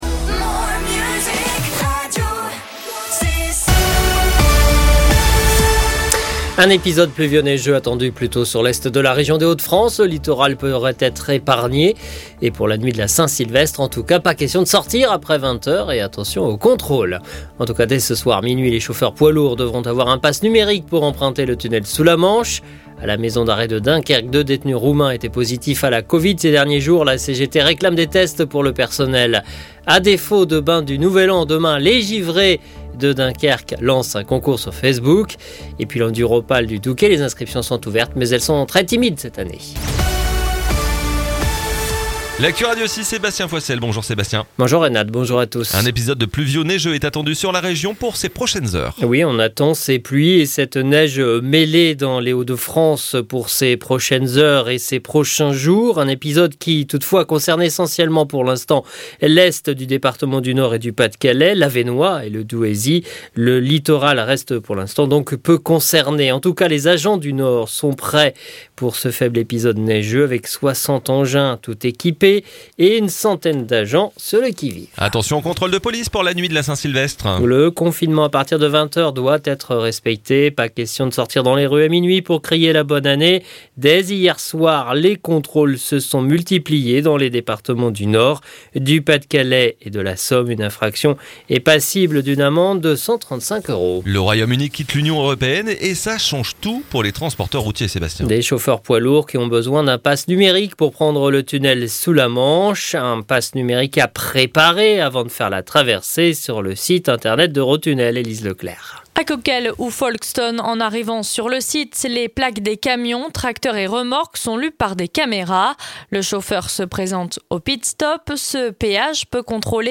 Le journal du jeudi 31 décembre 2020 sur la Côte d'Opale et Picarde